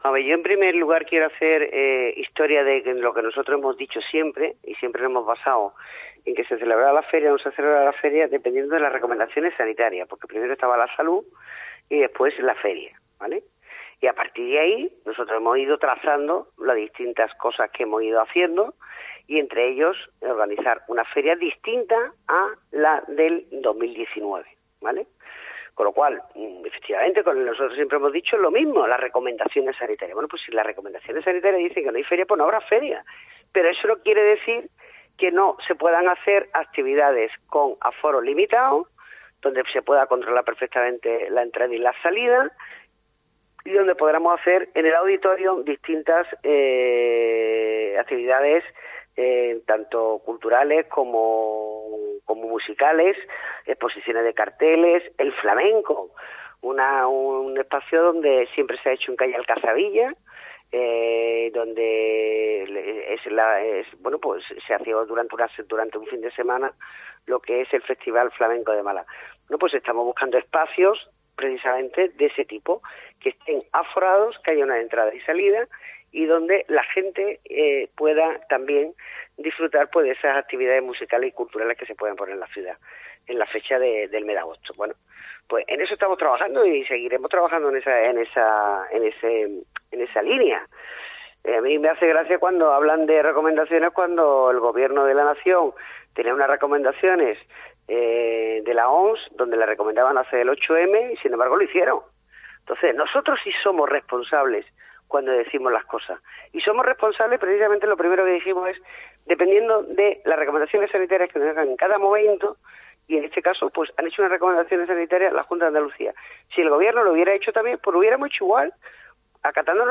Lo ha confirmado en declaraciones a la Cadena COPE la concejala de Fiestas, Teresa Porras.